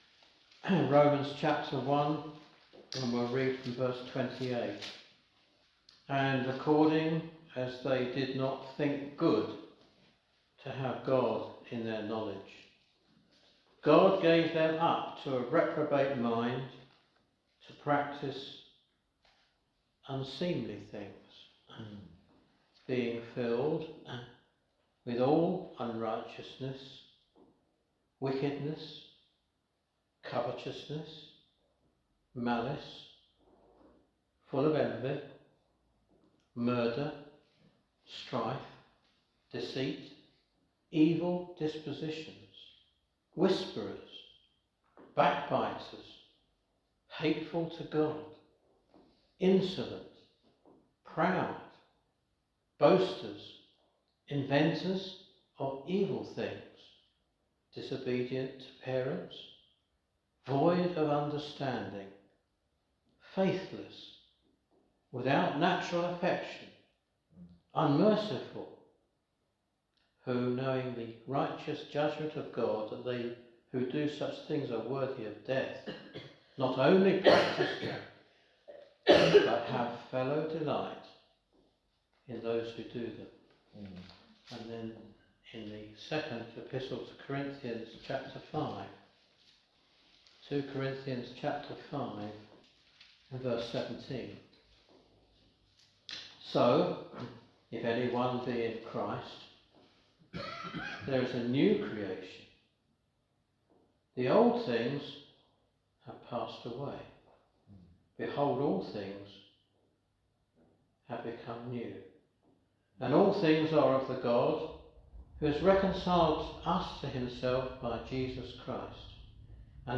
Discover the true ugliness of sin and the beauty of God’s grace that redeems us. A gospel message revealing how sin separates, destroys, and yet points us to our need for the Saviour.